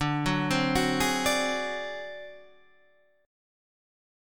D 7th Flat 5th